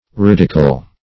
ridicle - definition of ridicle - synonyms, pronunciation, spelling from Free Dictionary Search Result for " ridicle" : The Collaborative International Dictionary of English v.0.48: Ridicle \Rid"i*cle\, n. Ridicule.
ridicle.mp3